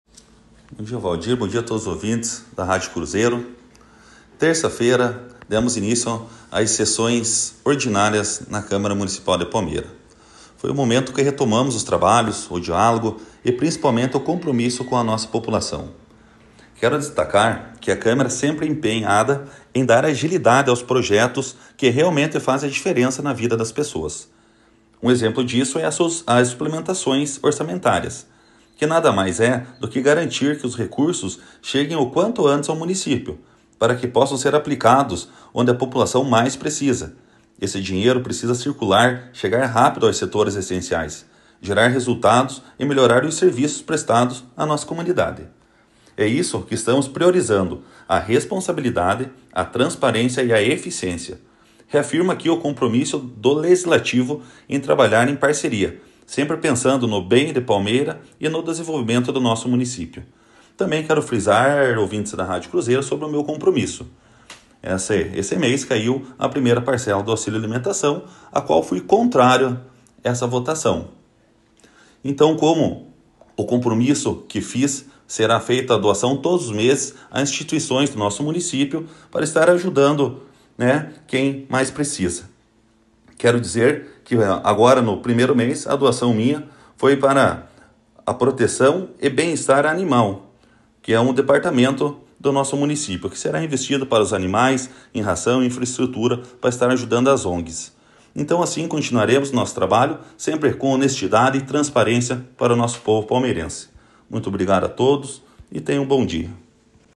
Em entrevista concedida à Rádio Cruzeiro, o presidente da Câmara Municipal de Palmeira, Diego Zanetti (Republicanos), destacou que o Legislativo seguirá concentrando esforços na análise e votação de projetos que tenham impacto direto na vida da população.